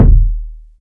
KICK.119.NEPT.wav